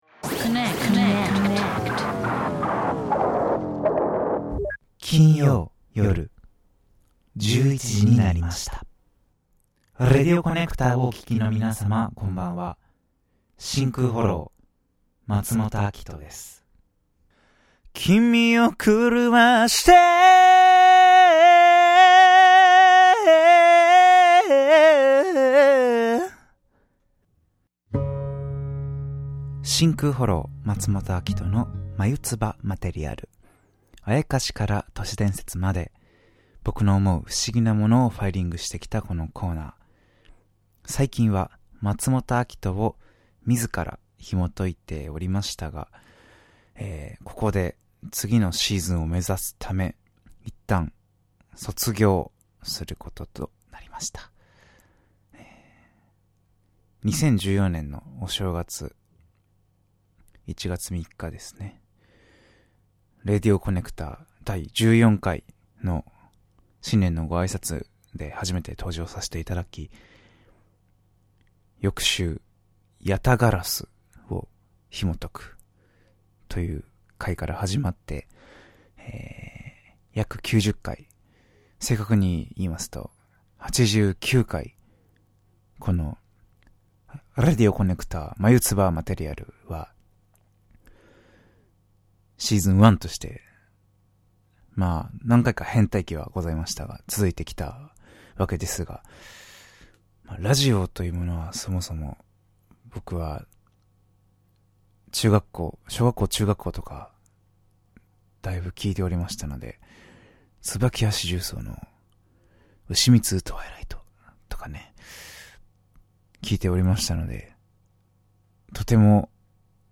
番組の始まりを告げる魅力的な生歌。